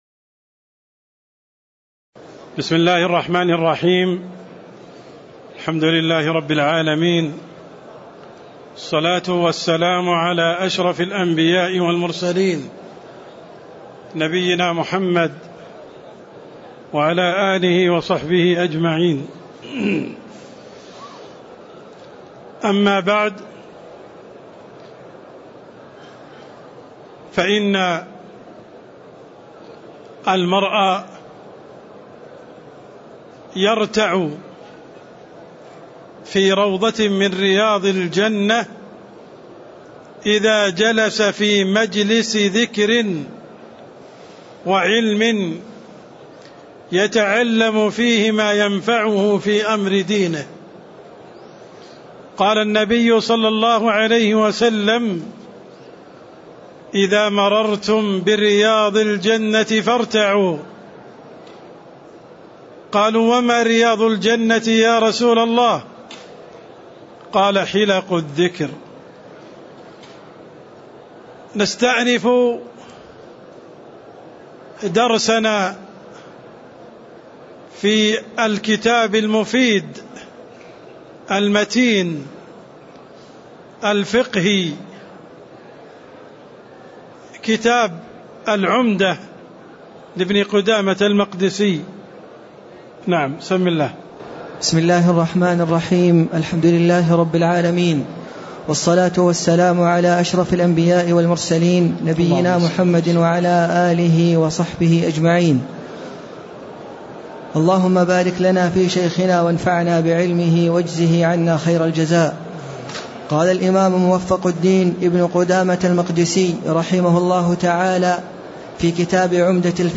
تاريخ النشر ٧ محرم ١٤٣٧ هـ المكان: المسجد النبوي الشيخ: عبدالرحمن السند عبدالرحمن السند باب صلاة المريض (15)